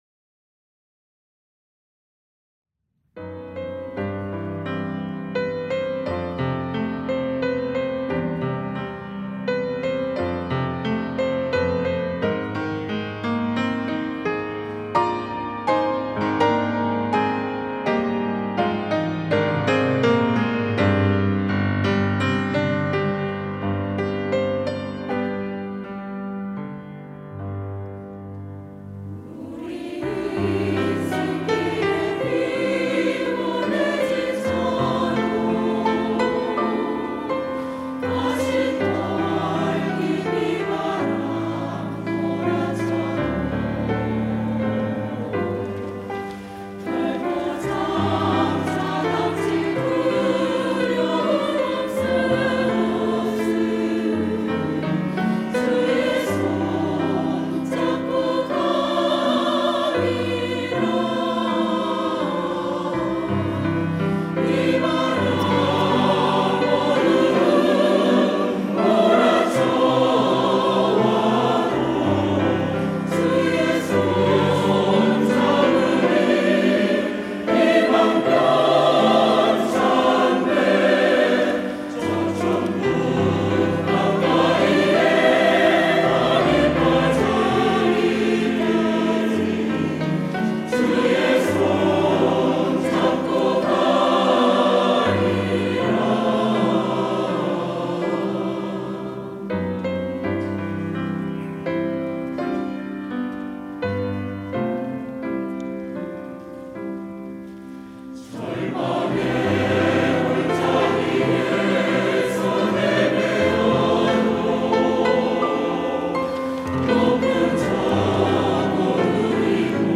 할렐루야(주일2부) - 주의 손 잡고 가리라
찬양대